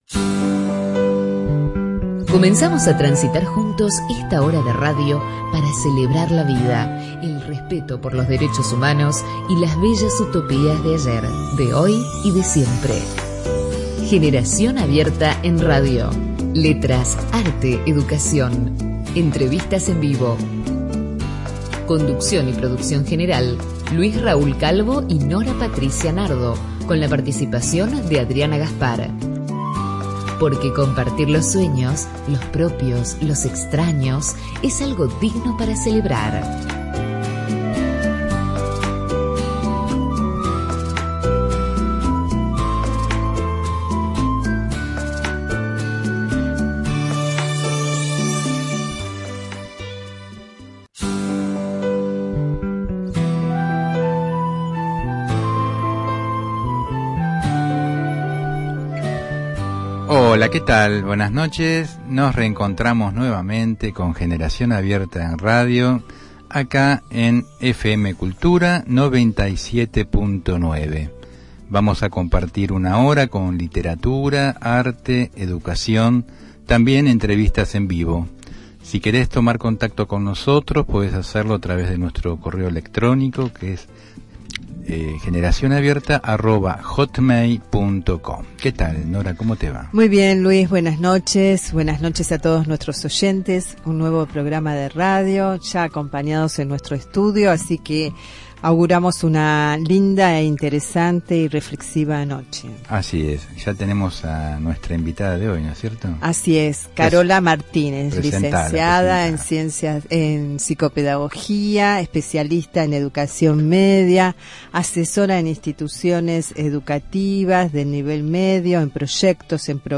Por la Radio AM 1010 “Onda Latina” , Buenos Aires, Argentina.